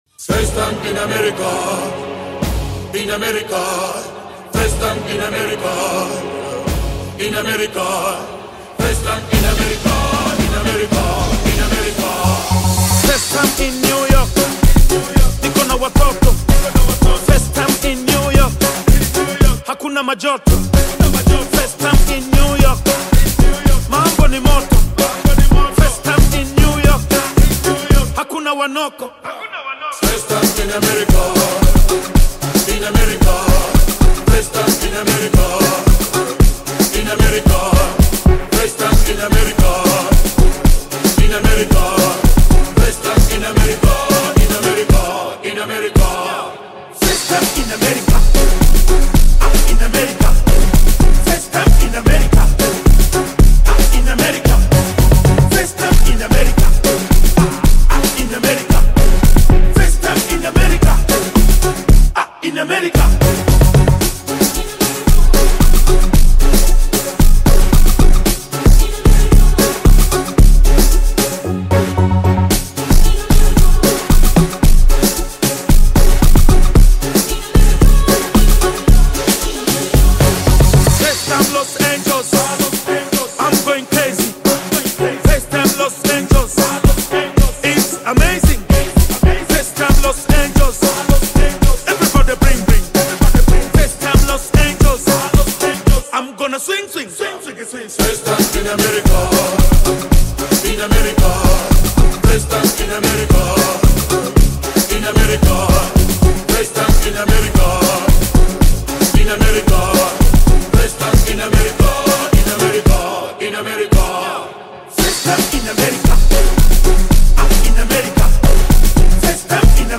Bongo Flava